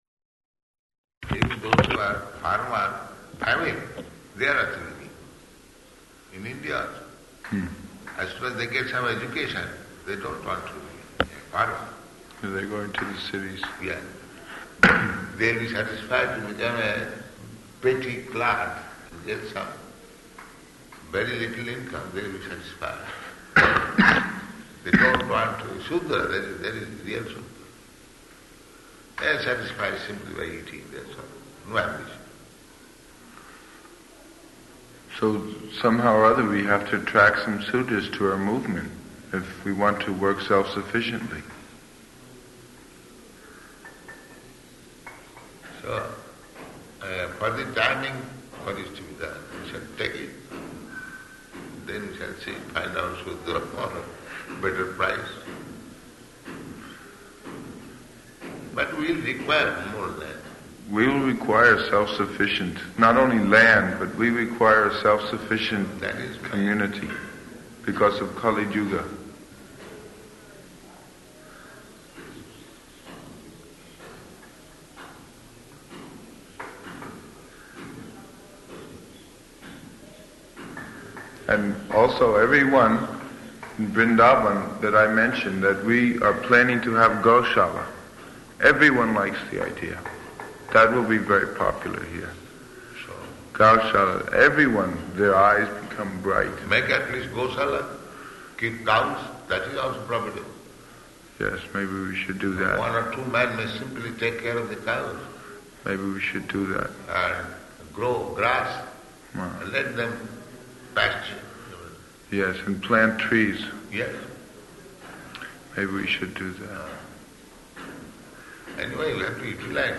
Room Conversation
Room Conversation --:-- --:-- Type: Conversation Dated: February 14th 1974 Location: Vṛndāvana Audio file: 740214R1.VRN.mp3 Prabhupāda: ...even those who are farmers [indistinct].